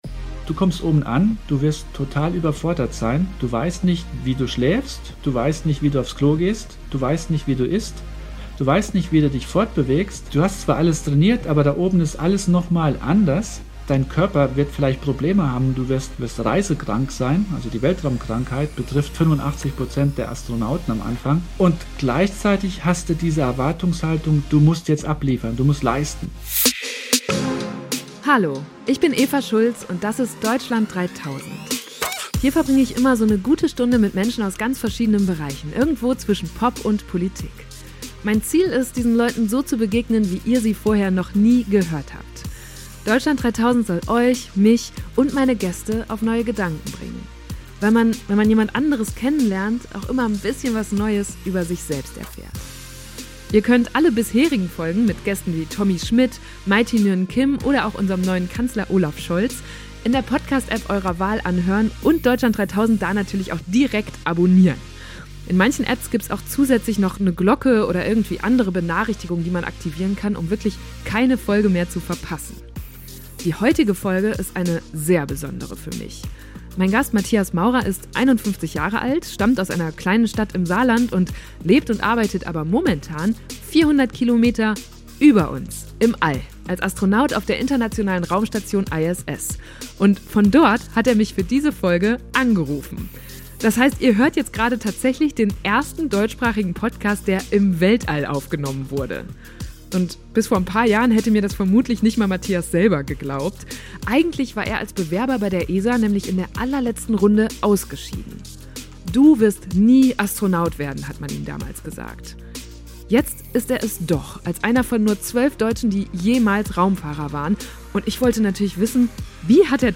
Von dort hat er mich für diese Folge angerufen. Das heißt, ihr hört jetzt gerade den ersten deutschsprachigen Podcast, der im Weltall aufgenommen wurde.
Deswegen ist diese Folge auch ein bisschen länger als üblich, wir haben nämlich beide Gespräche, das Vorher-Nachher, hintereinander gepackt, und das macht es, finde ich, noch besonderer.